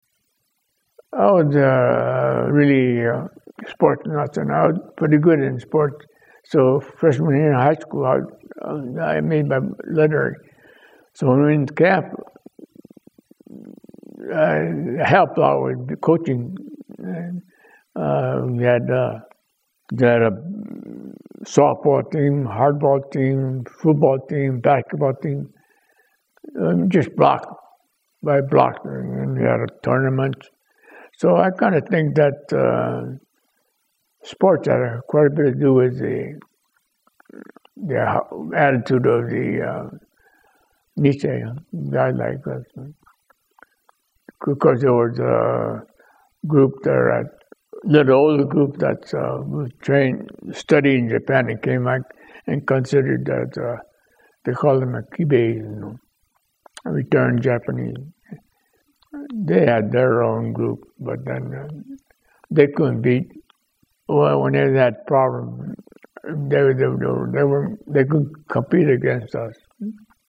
You can also read the TRANSCRIPT of the above interviews.